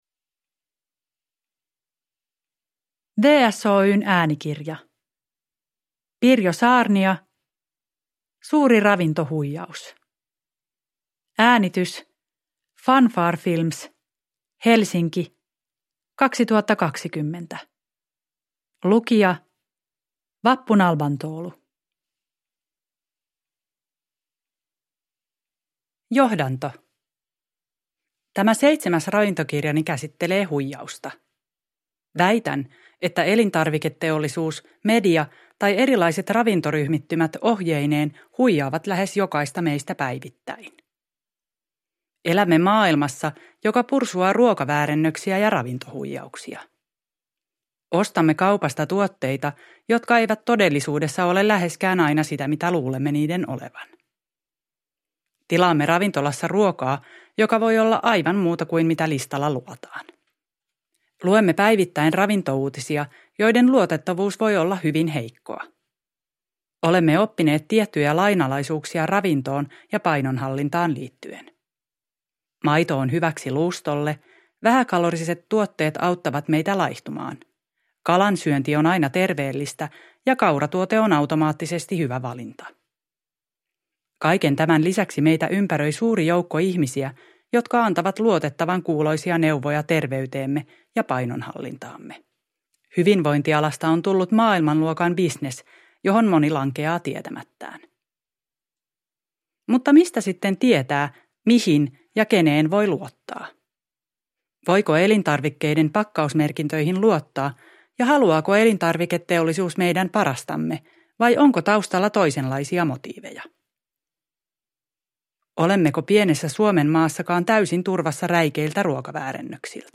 Suuri ravintohuijaus – Ljudbok – Laddas ner